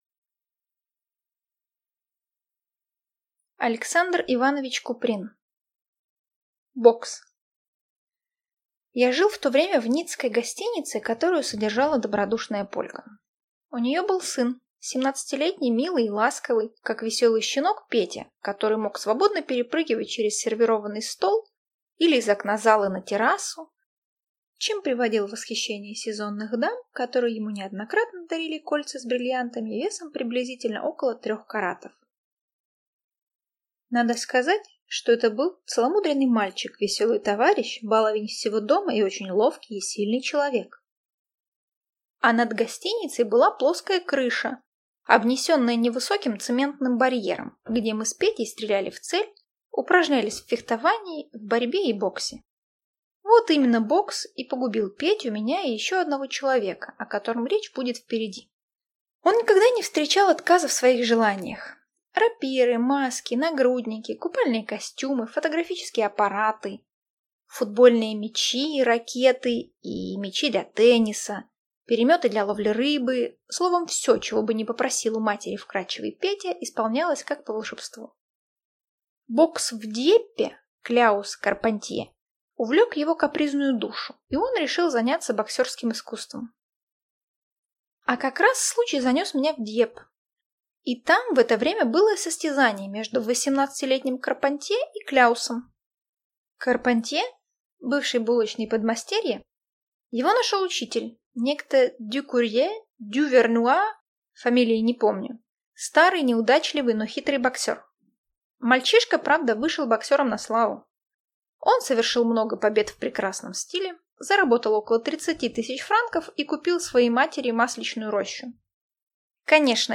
Аудиокнига Бокс | Библиотека аудиокниг